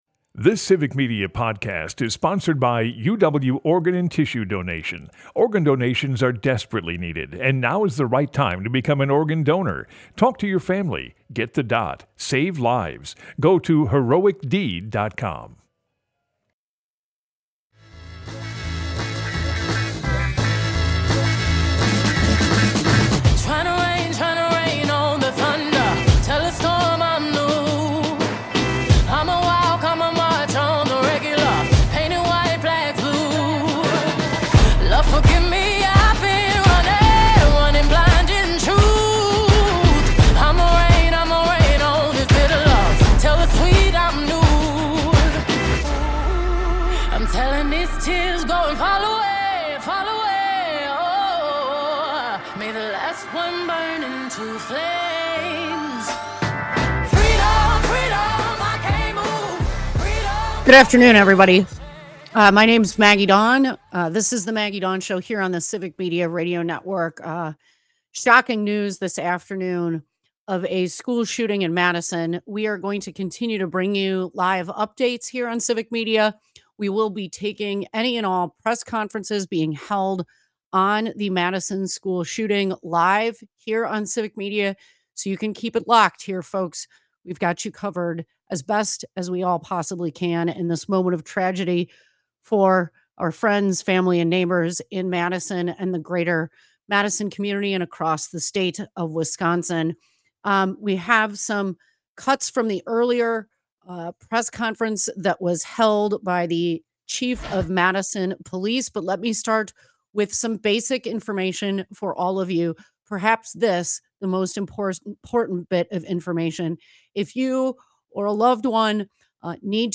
We also listen in to the live press conference with Madison Police Chief Barnes and other local officials.